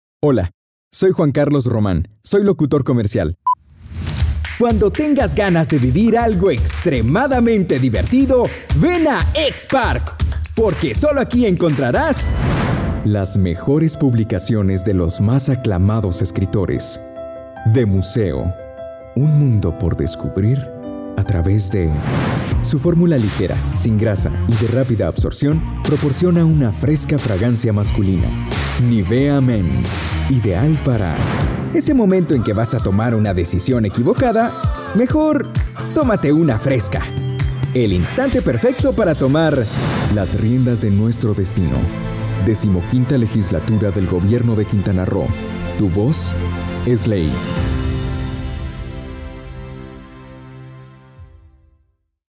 西班牙语男声
低沉|激情激昂|大气浑厚磁性|沉稳|娓娓道来|科技感|积极向上|时尚活力|神秘性感|调性走心|感人煽情|素人